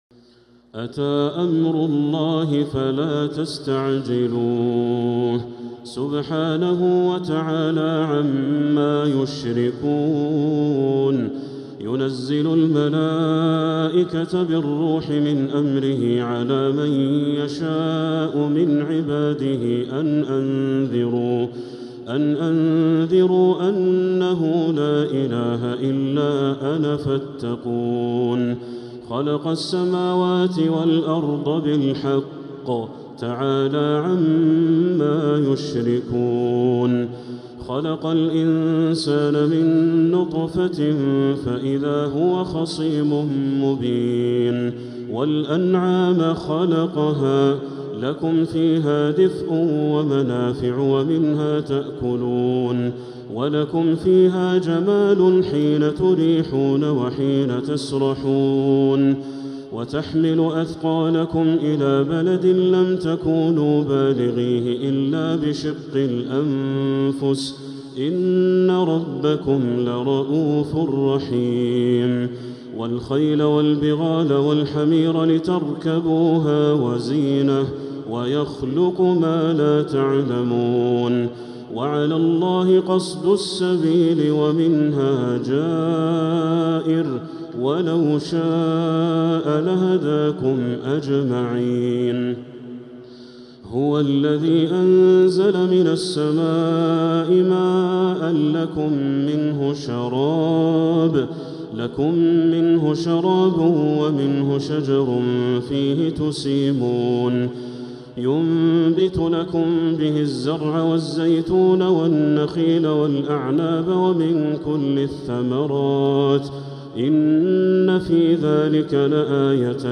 لأول مرة! سورة النحل كاملة للشيخ بدر التركي من المسجد الحرام | Surat An-Nahl Badr Al-Turki > السور المكتملة للشيخ بدر التركي من الحرم المكي 🕋 > السور المكتملة 🕋 > المزيد - تلاوات الحرمين